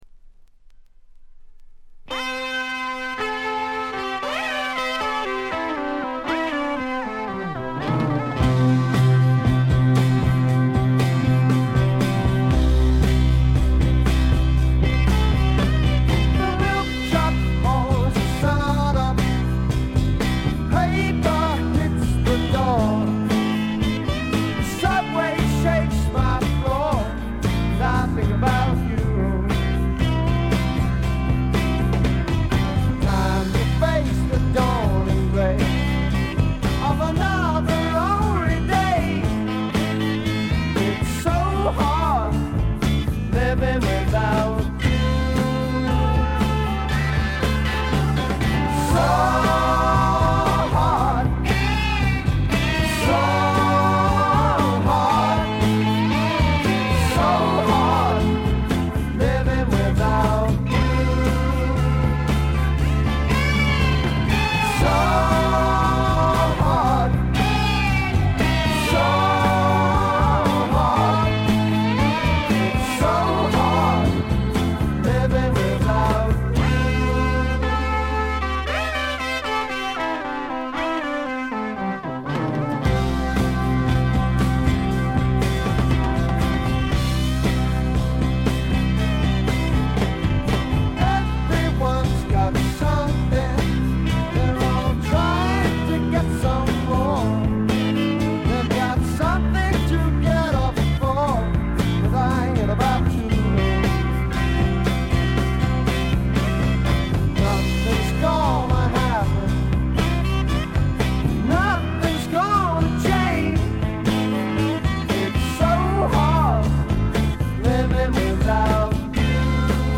試聴曲は現品からの取り込み音源です。
Recorded at Maximum Sound, I.B.C. Studios